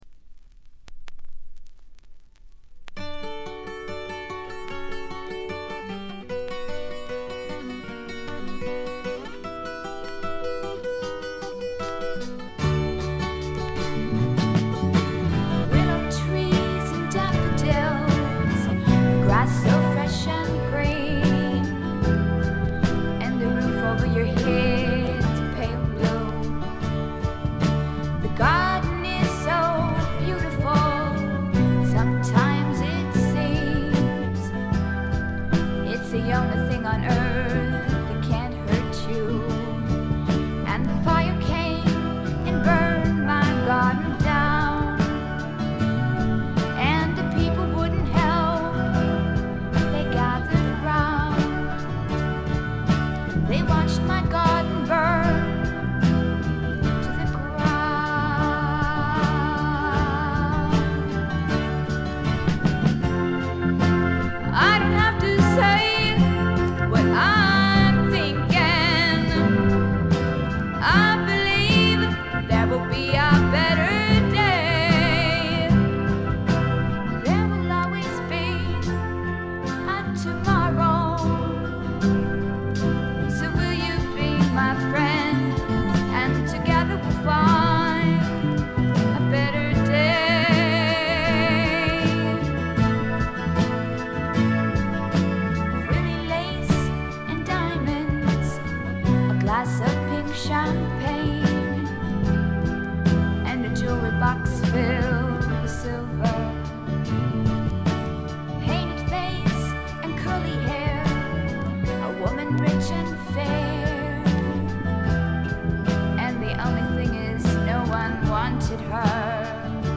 静音部での軽微なバックグラウンドノイズ。
ペンシルヴェニア出身の大人気のフィメール・フォーキー。
全曲自作の素晴らしい楽曲、清楚な歌声、美しいアコギの音色、60年代気分を残しているバックの演奏、たなびくフルートの音色。
試聴曲は現品からの取り込み音源です。